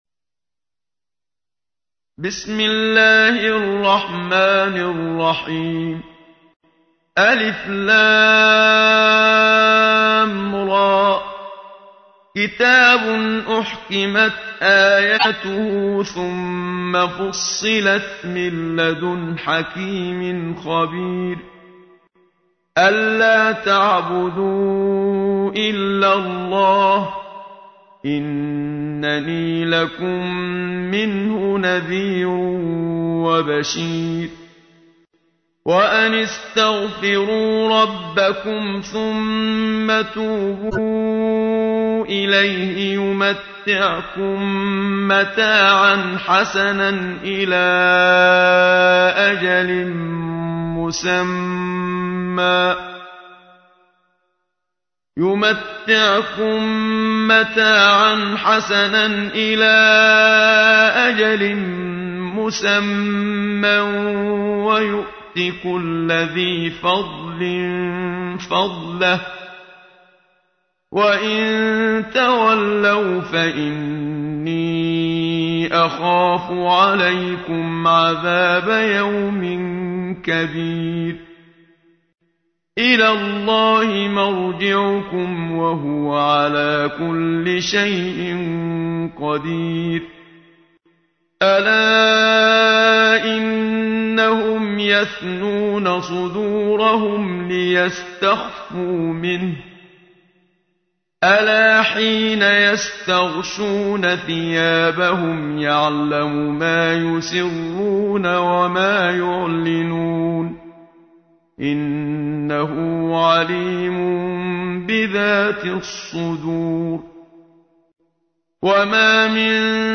تحميل : 11. سورة هود / القارئ محمد صديق المنشاوي / القرآن الكريم / موقع يا حسين